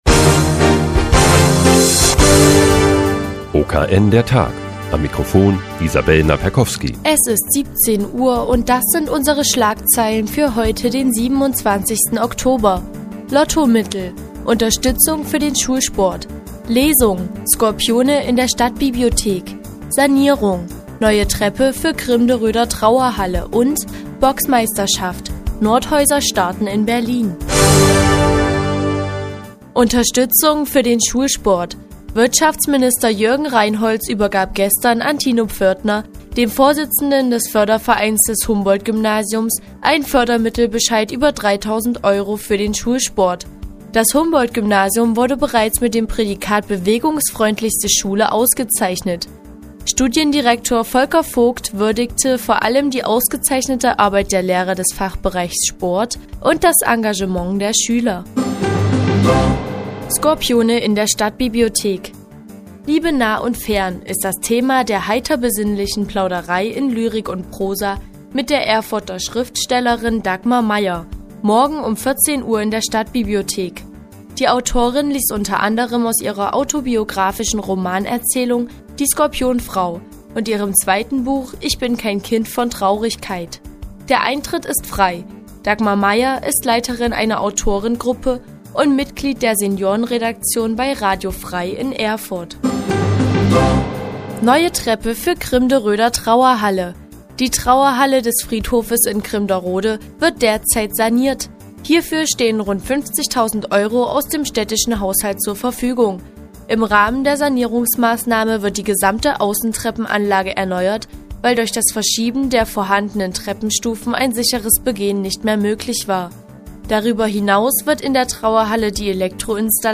Die tägliche Nachrichtensendung des OKN ist nun auch in der nnz zu hören. Heute geht es um eine Lesung in der Stadtbibliothek und eine neue Treppe für die Krimderöder Trauerhalle.